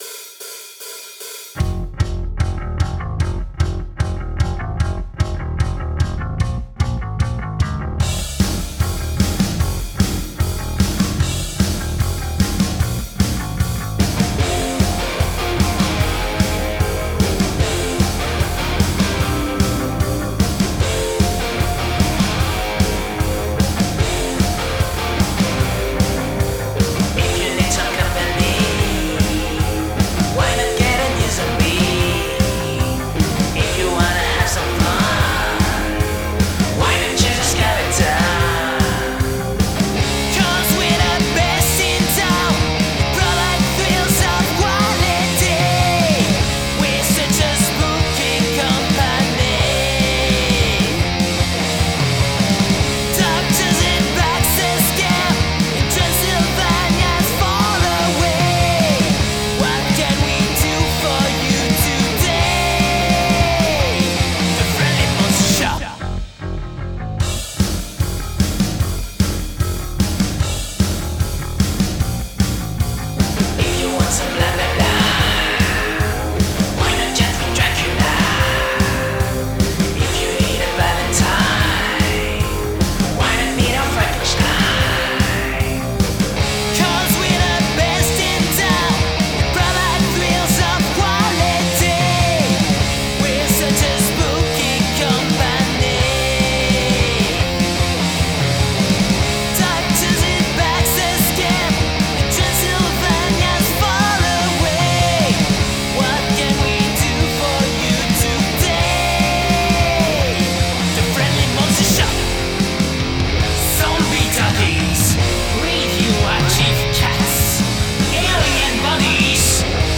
Rock > Punk